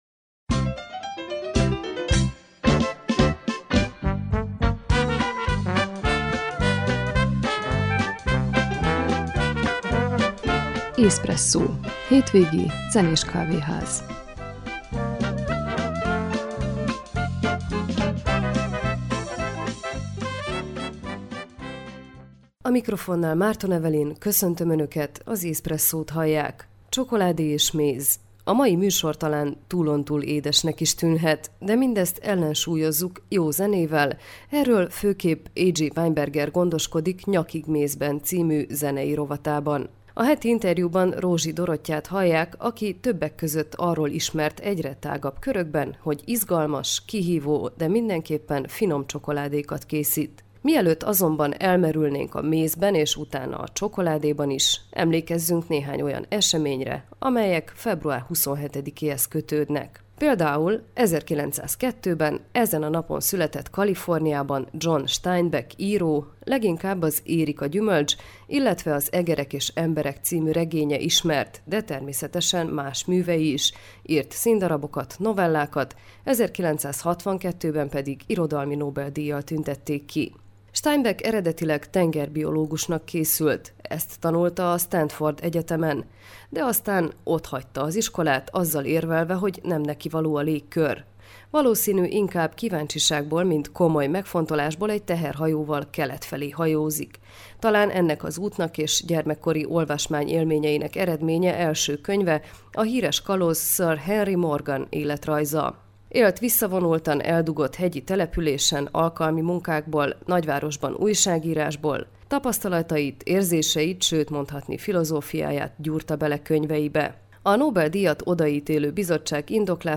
illetve a Heti interjút